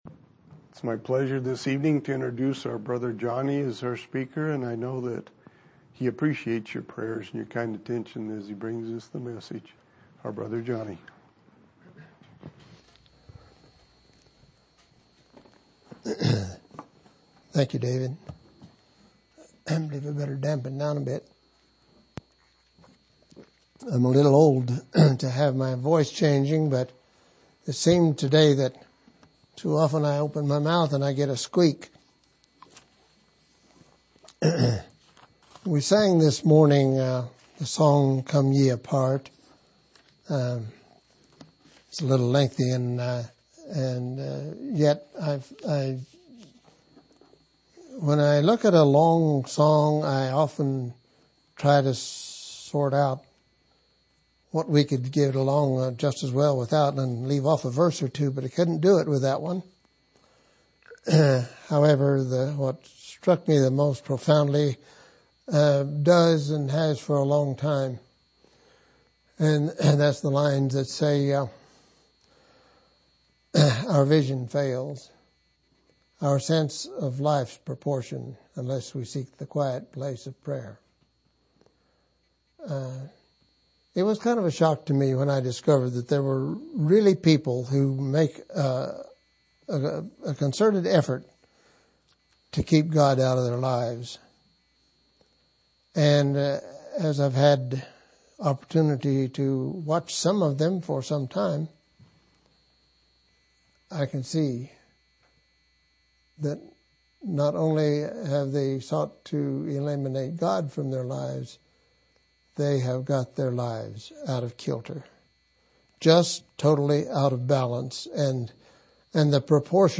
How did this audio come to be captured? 10/6/2013 Location: Grand Junction Local Event